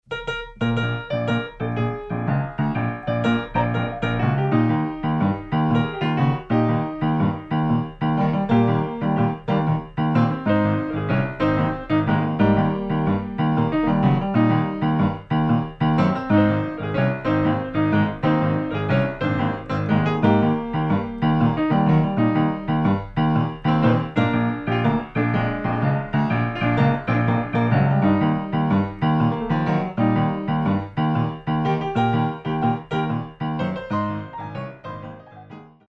Style: Boogie Woogie Piano